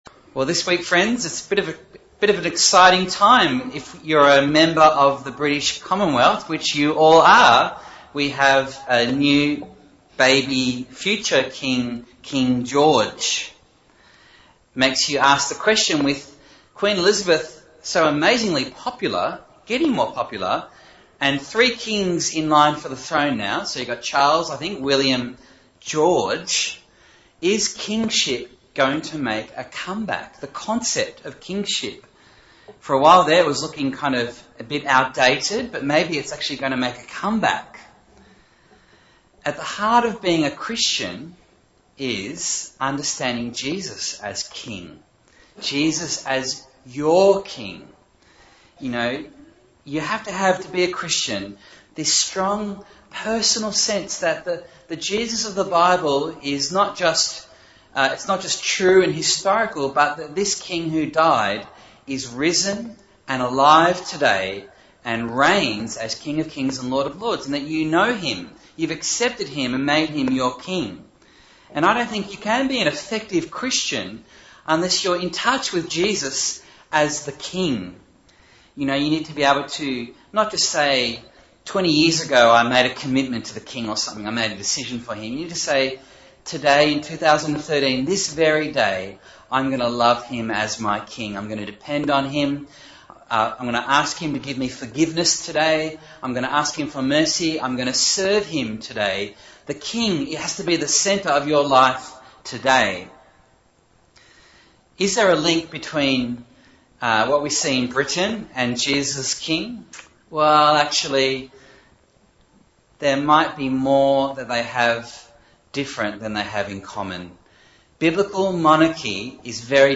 Bible Text: 1 Samuel 3:1-21 | Preacher